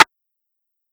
Perc [ flex ].wav